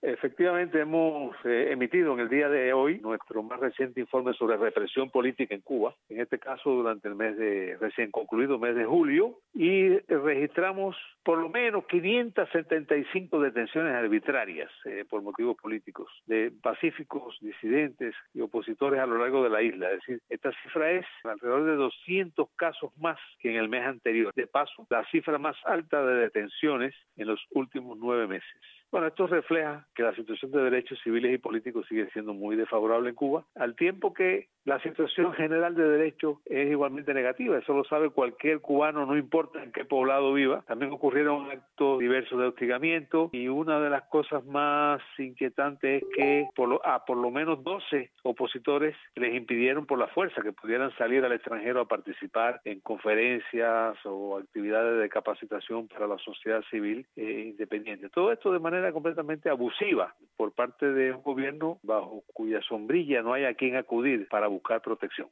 Entrevista a Elizardo Sánchez